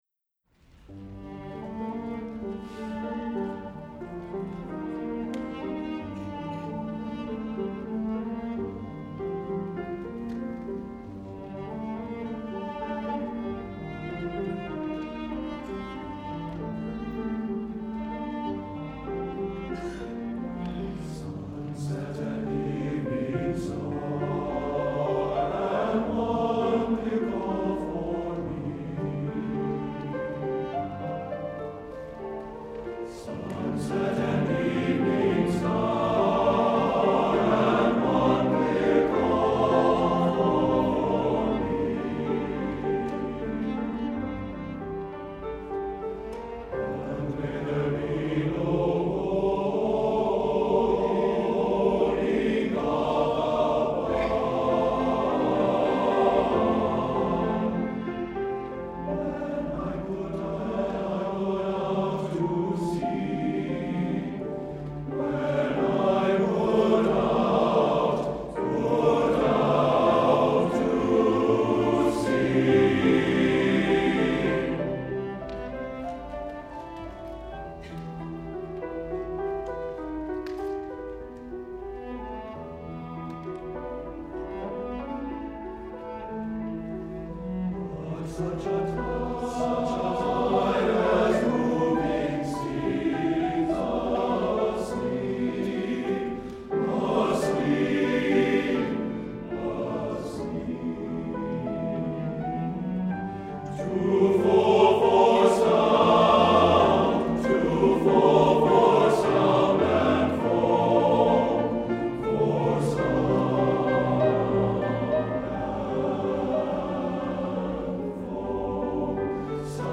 Voicing: TTBB, Piano and Cello